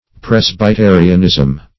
Presbyterianism \Pres`by*te"ri*an*ism\, n. [Cf. F.